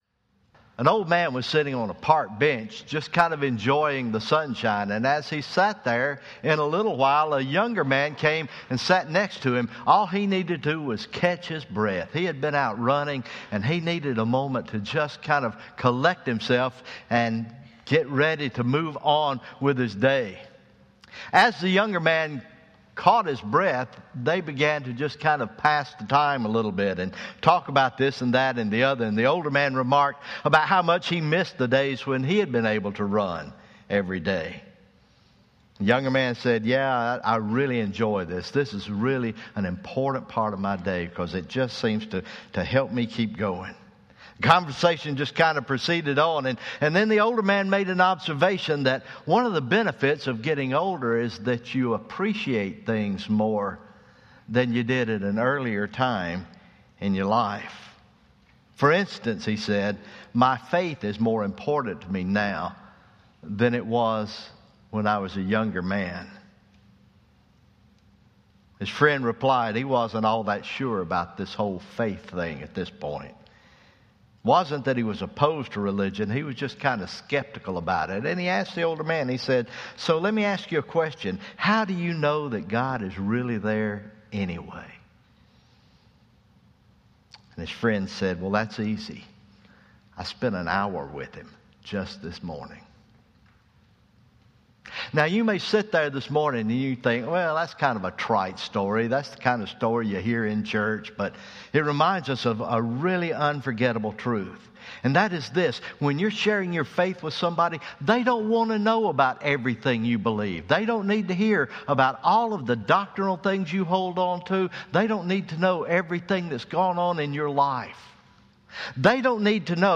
First Hand Faith – First Baptist Church of Birmingham, Alabama